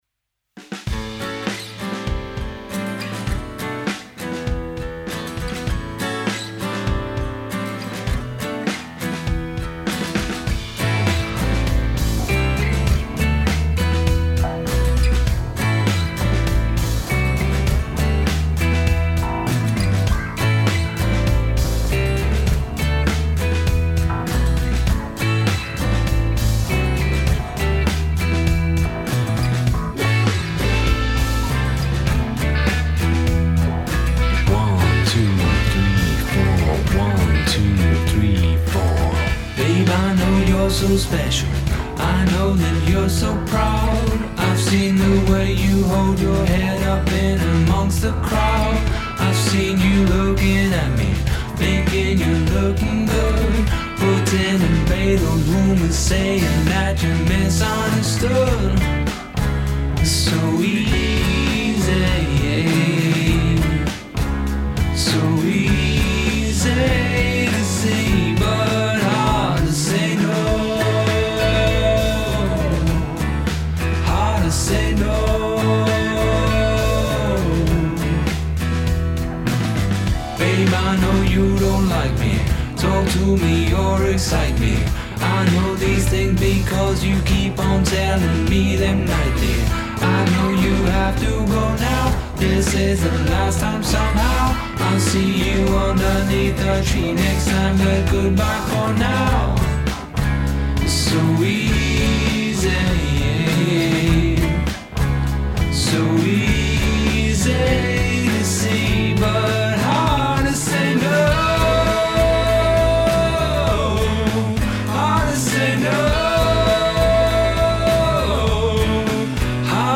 Here's mix 2, which likely is my final mix.